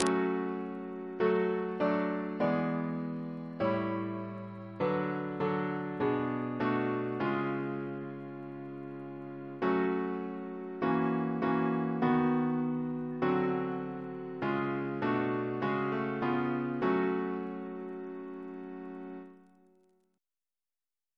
Double chant in F♯ minor Composer: George Mursell Garrett (1834-1897), Organist of St. John's College, Cambridge Reference psalters: ACP: 335